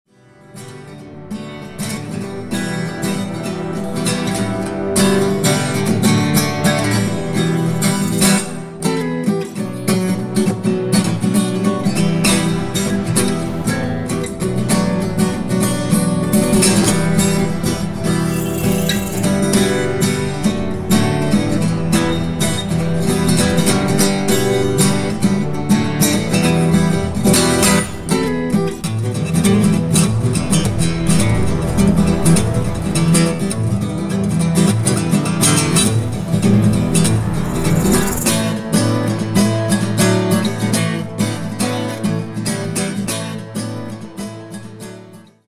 Pure instrumental timeless guitar music...
Al l tracks composed, played, arranged and mixed by yours.